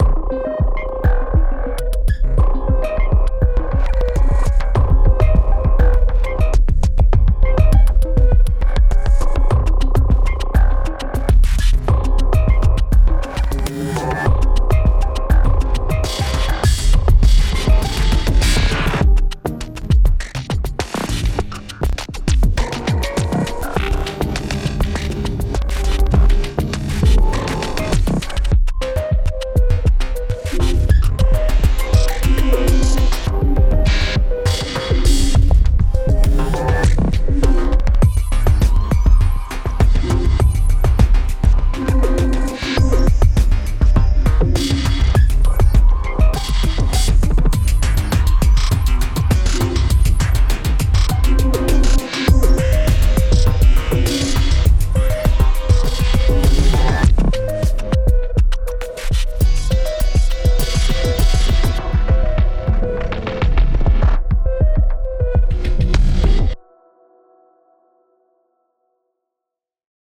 A unique drum machine based on modular gear
• 170 presets based on over 320 samples, designed with early digital devices
Audio demos
Command aggressive, futuristic beats with intriguing twists and powerful real-time morphing. Build distinctive beats fast using the intuitive sequencer, randomizer, and mutator – then transform, warp, and enhance them with searing distortion, reverb, delay, and new sidechaining controls.